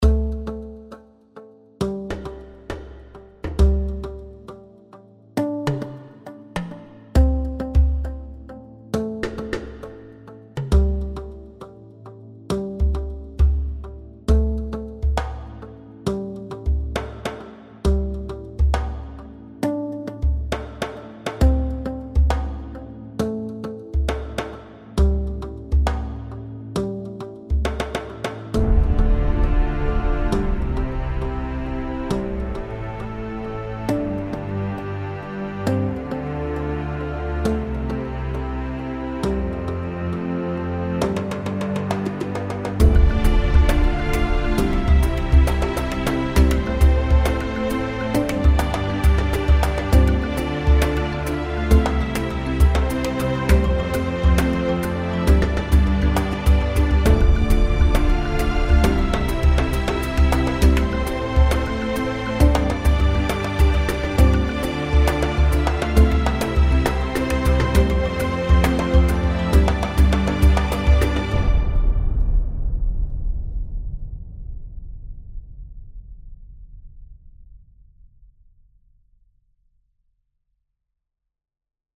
epique - percussions - violons - paysage - aerien